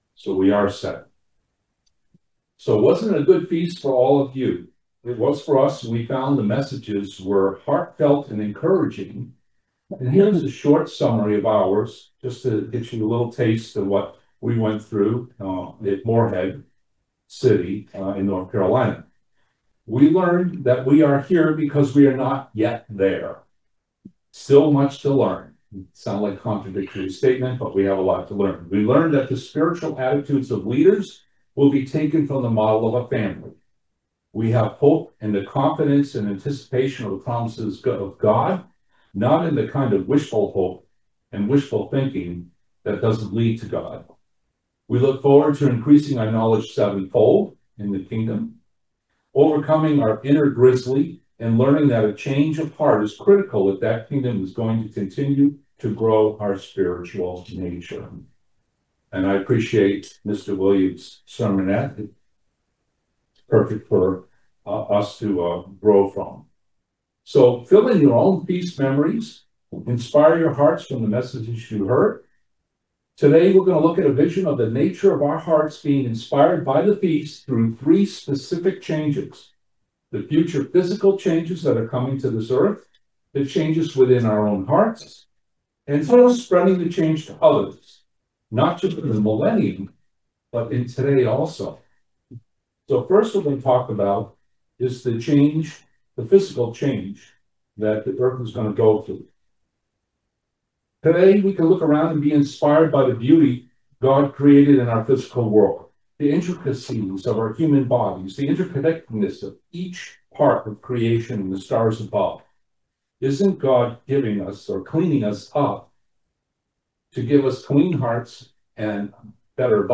What is the vision of the nature of our heart? Do we welcome the changes of our hearts ? Join us for this eye opening video sermon.